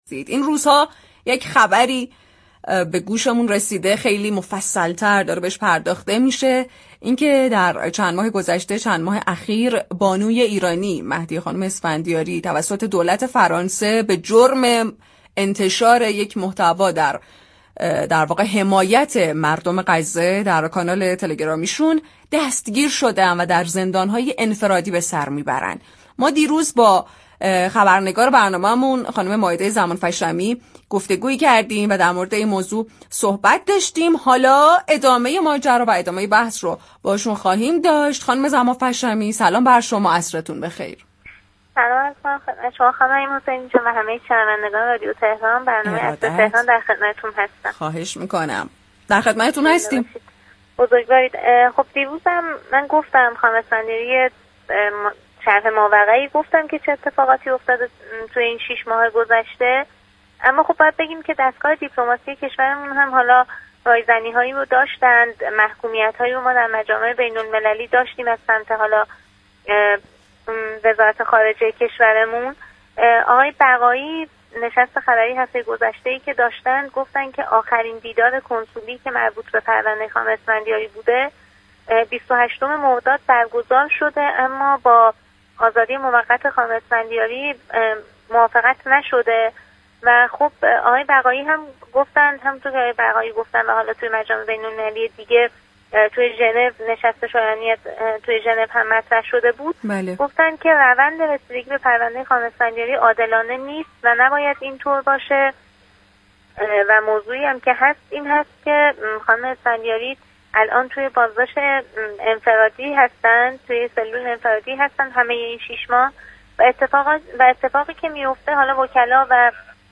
در گفت‌وگو با رادیو تهران
گفت و گوی تلفنی با برنامه «عصر تهران» رادیو تهران